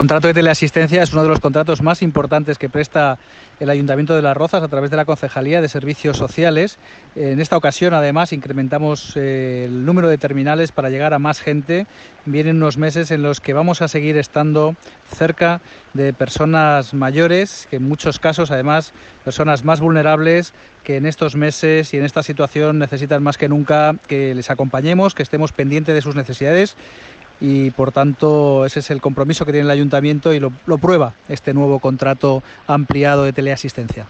Declaraciones del alcalde José de la Uz